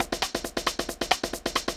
K-7 Percussion.wav